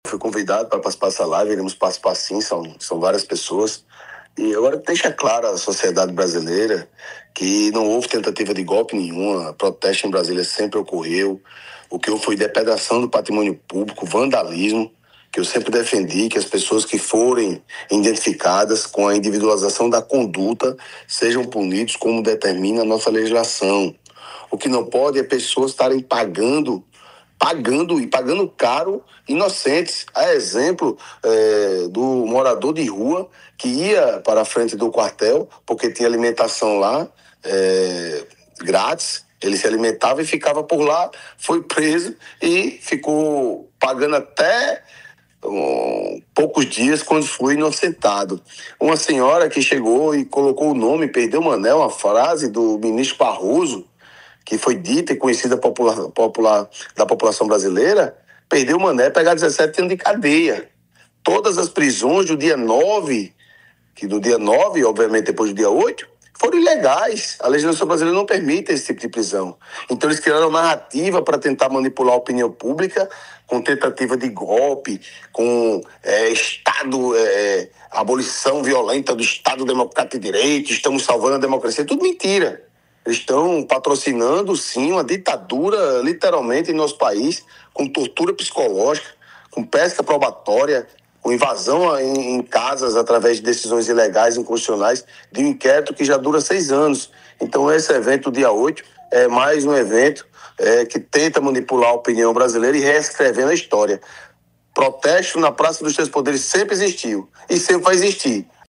Os comentários do deputado foram registrados pelo programa Correio Debate, da 98 FM, de João Pessoa, nesta quarta-feira (08/01).